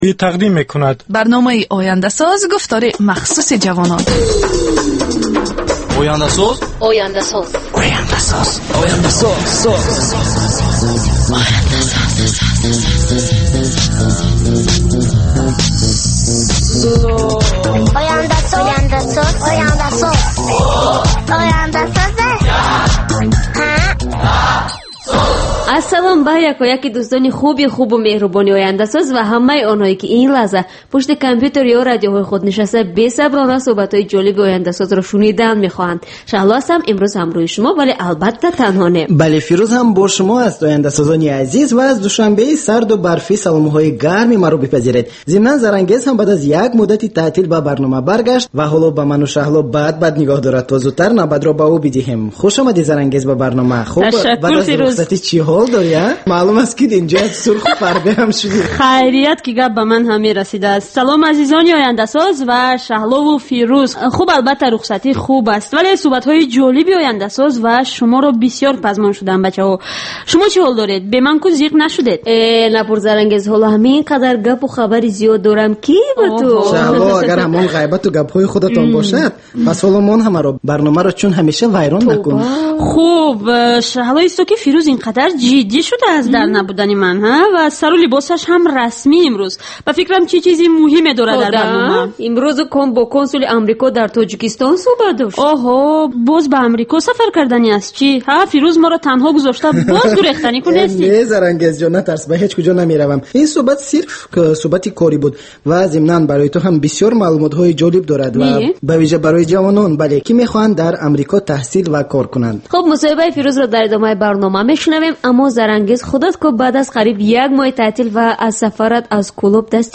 Бар илова, дар ин гуфтор таронаҳои ҷаззоб ва мусоҳибаҳои ҳунармандон тақдим мешавад.